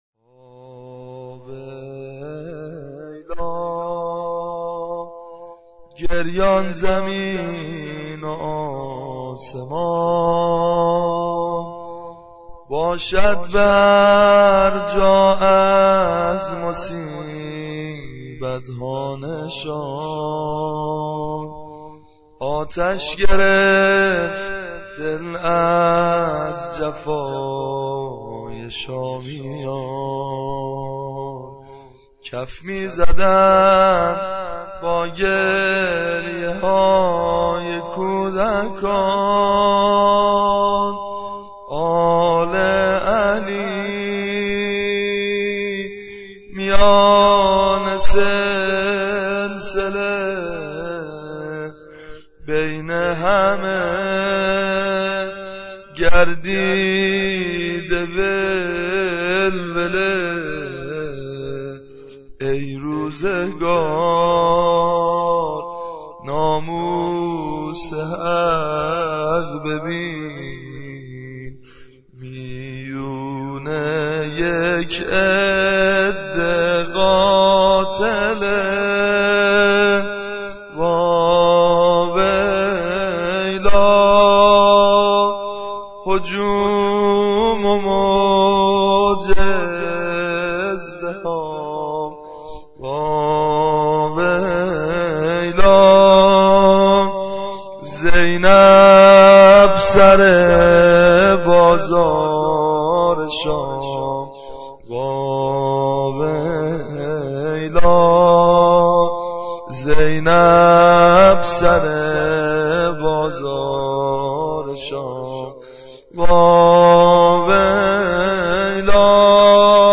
اشعار كاروان اسرا در شام -زمزمه -( واویلا ، گریان زمین و آسمان )